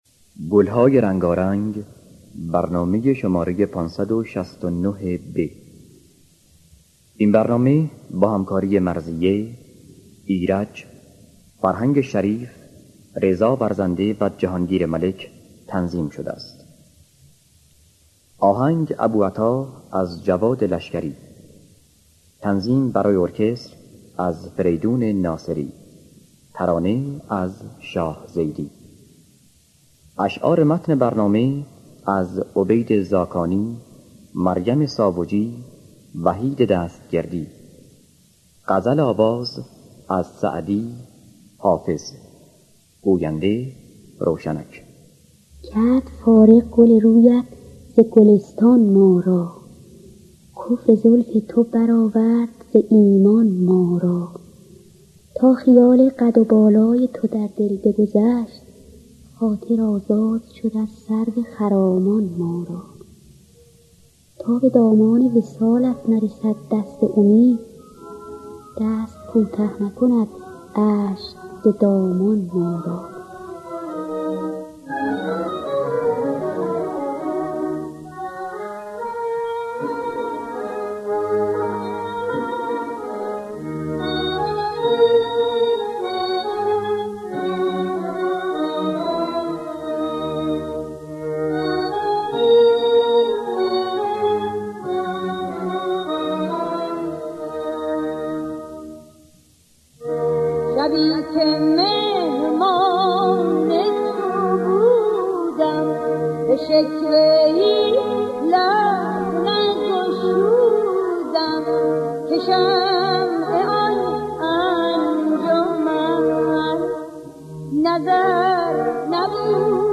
دانلود گلهای رنگارنگ ۵۶۹ب با صدای ایرج، مرضیه در دستگاه ابوعطا.